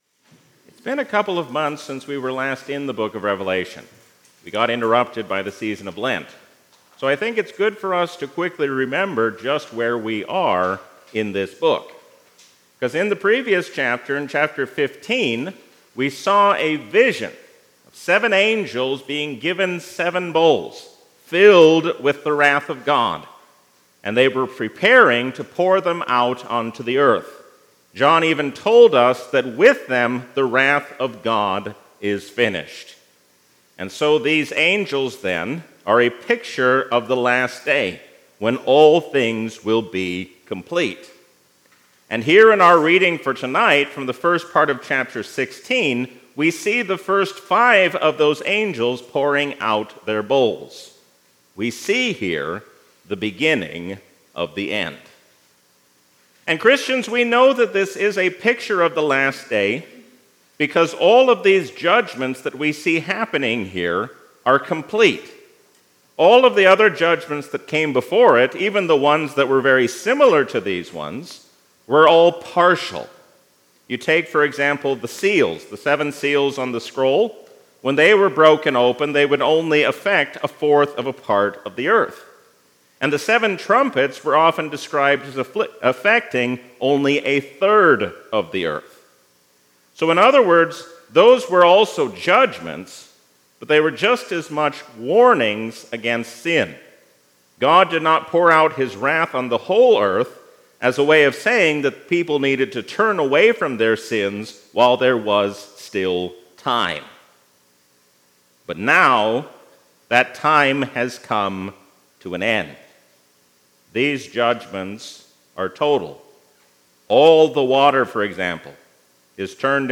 A sermon from the season "Easter 2024." Do not give in to the allure of this world, but stand fast, knowing that God will soon bring all evil to an end.